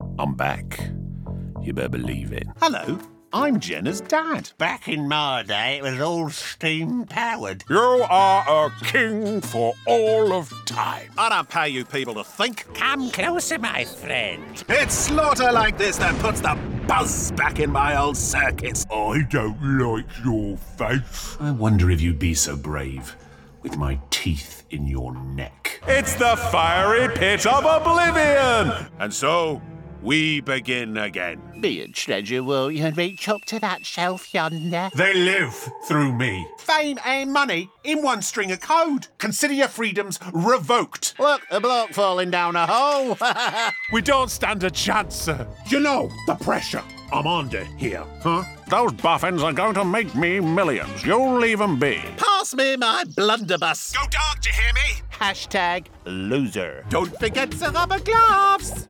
Compilation Showreel
Male
Neutral British
Playful
Quirky
Upbeat